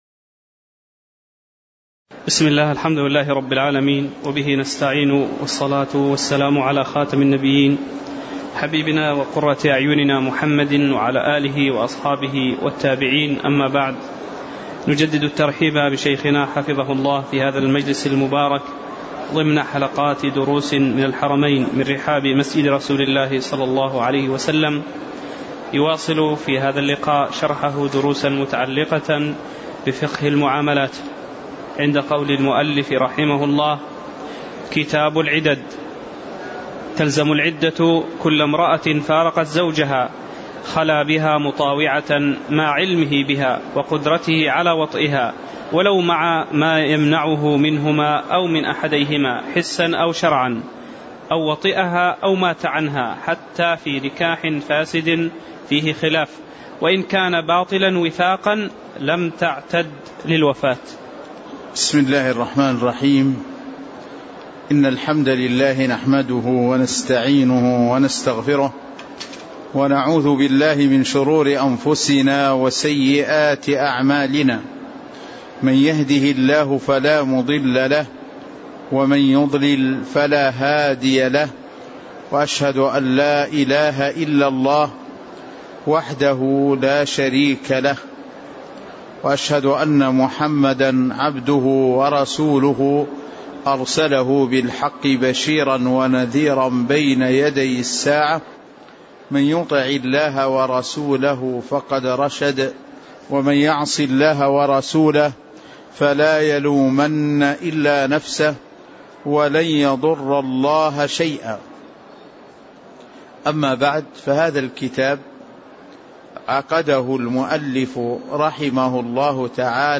تاريخ النشر ١٦ محرم ١٤٣٨ هـ المكان: المسجد النبوي الشيخ